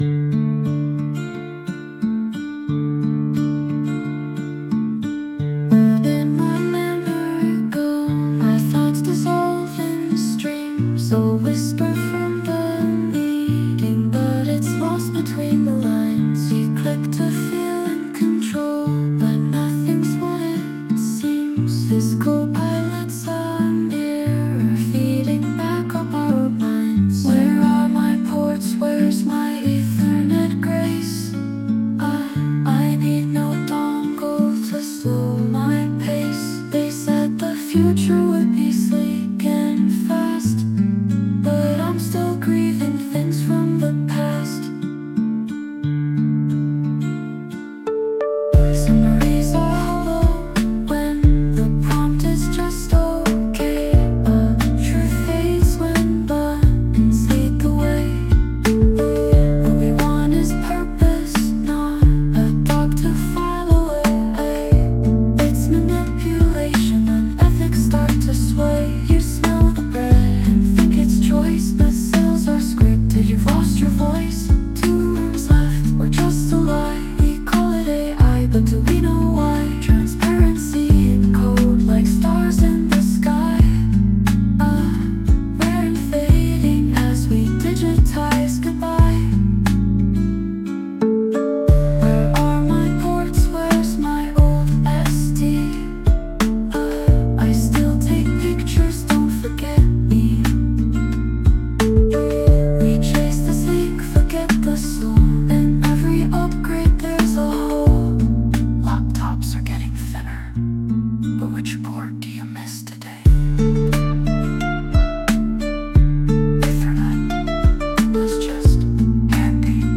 Dit lied is volledig met AI gegenereerd. De teksten zijn afkomstig van de interviews van aflevering 6.